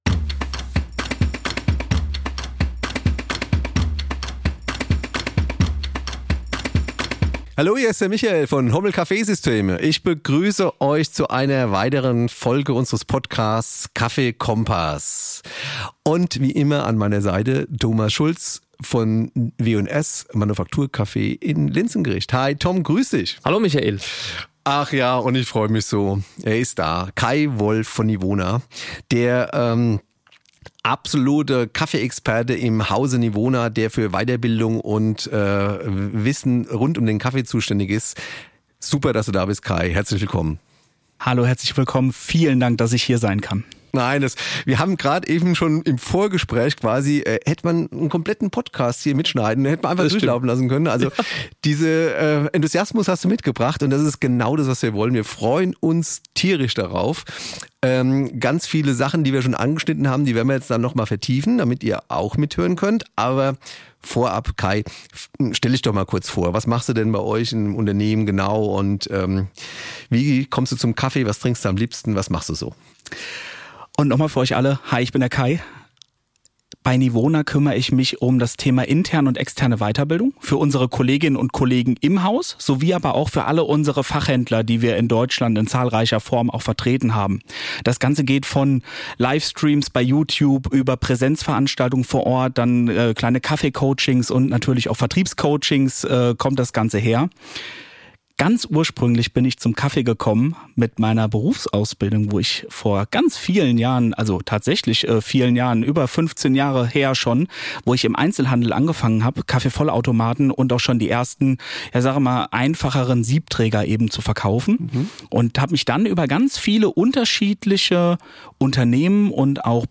Beides sprüht dem Hörer der KaffeeKOMPASS Podcast-Reihe ab der ersten Sekunde entgegen, wenn zwei ausgewiesene Fachleute rund um das schwarze Gold fachsimpeln.